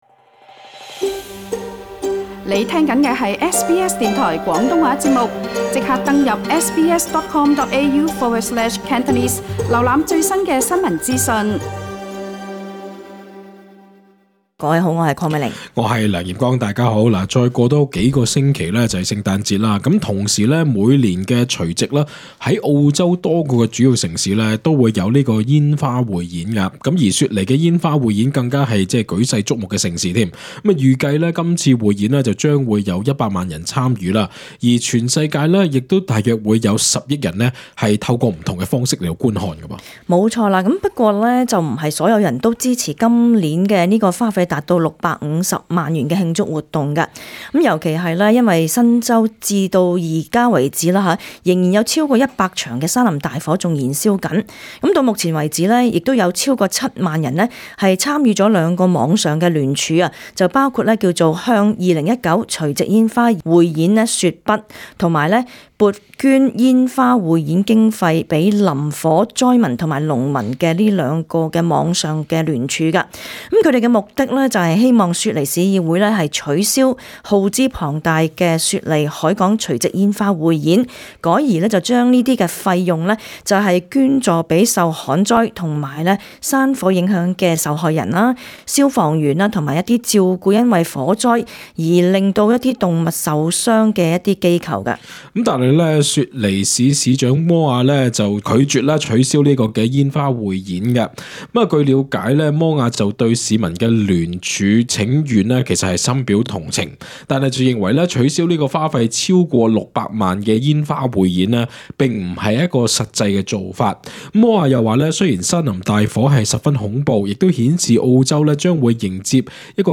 本節目內嘉賓及聽眾意見並不代表本台立場 READ MORE 【大眾論壇】你曾否試過被發不合理罰款單呢?